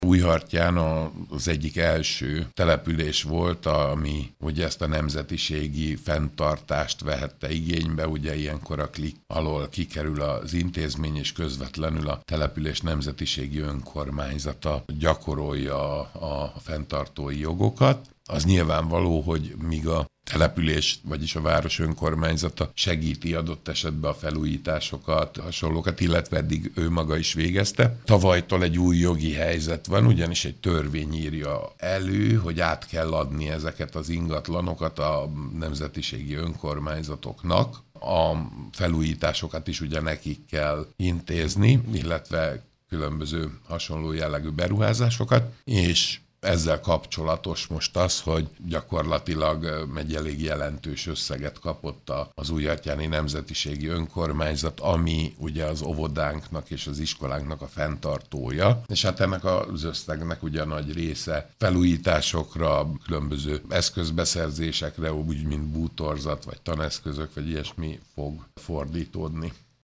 Az újhartyáni német nemzetiségi intézmények is átvették kormányzat kiegészítő támogatását, amelyet intézményfenntartásra fordíthatnak. Újhartyánban az óvoda és az iskola is a nemzetiségi önkormányzathoz tartozik. Schulcz József polgármestert hallják.